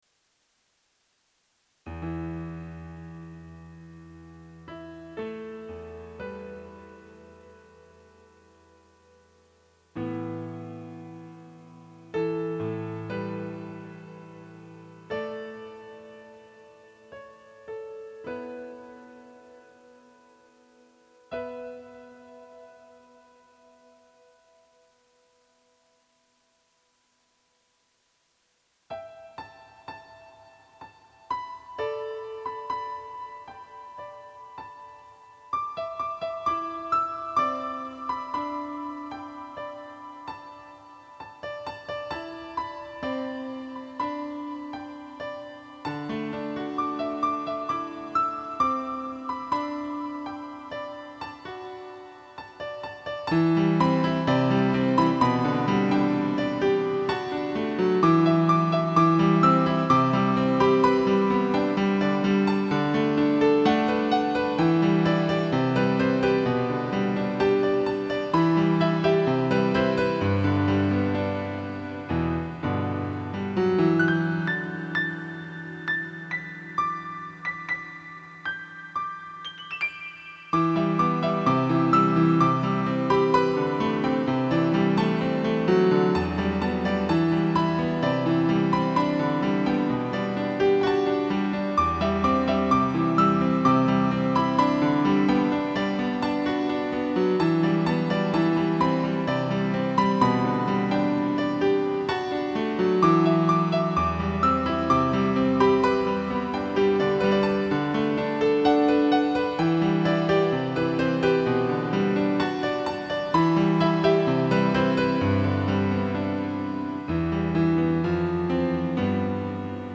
コメント 綺麗な旋律の中に、どこか悲しげな雰囲気を持ったピアノ曲です。